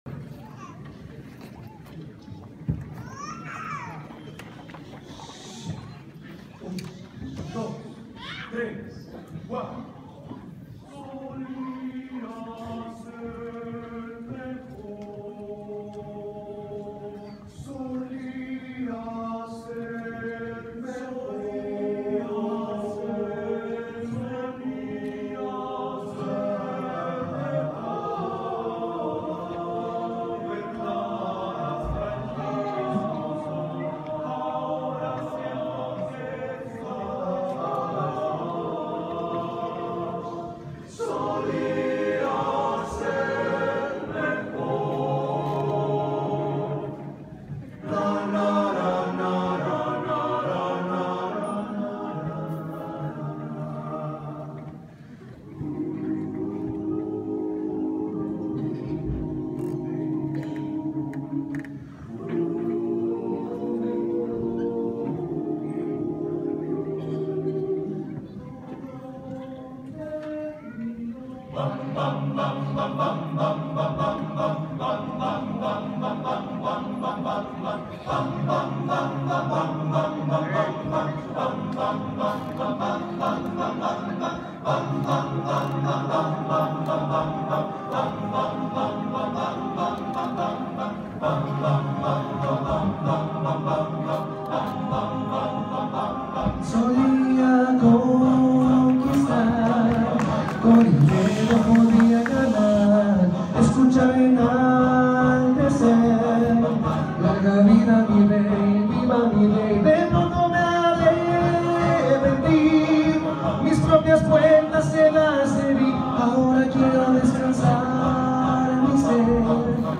Puebla